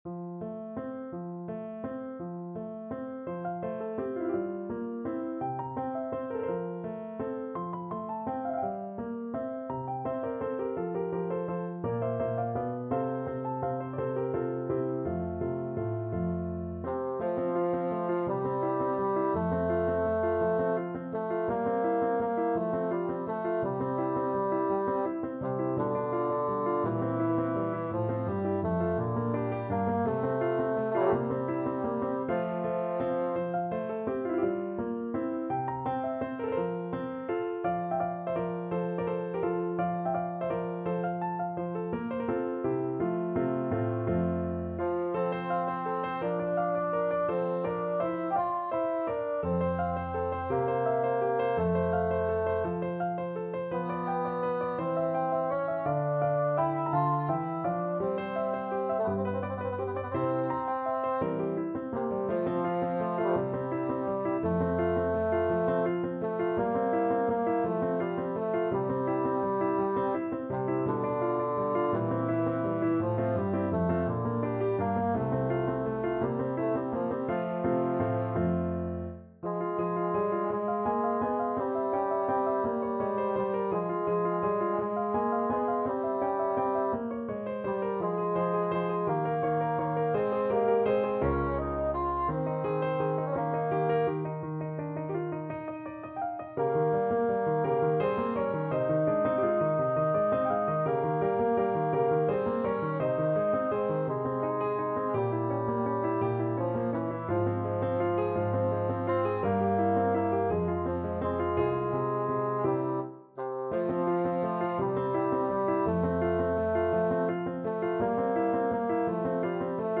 Classical Martini, Jean Paul Egide Plaisir dAmour Bassoon version
6/8 (View more 6/8 Music)
F major (Sounding Pitch) (View more F major Music for Bassoon )
Molto lento .=56
Classical (View more Classical Bassoon Music)